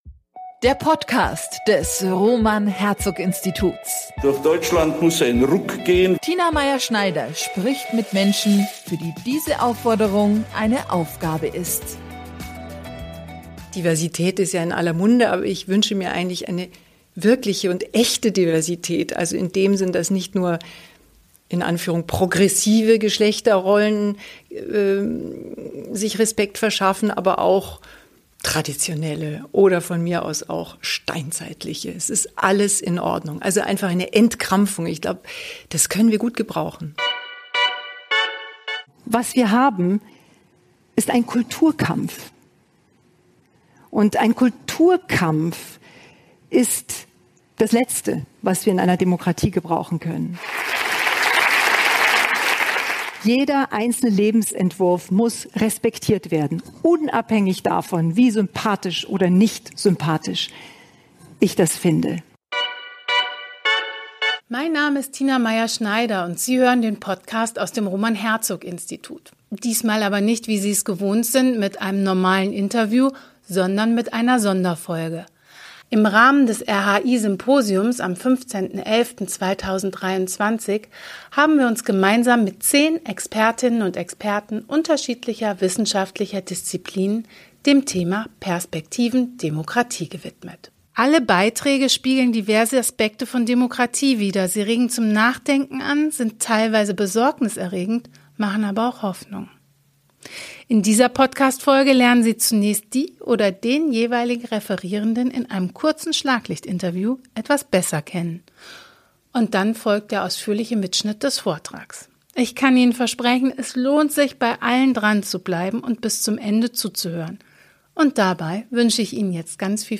Dann folgt der ausführliche Mitschnitt des Vortrags.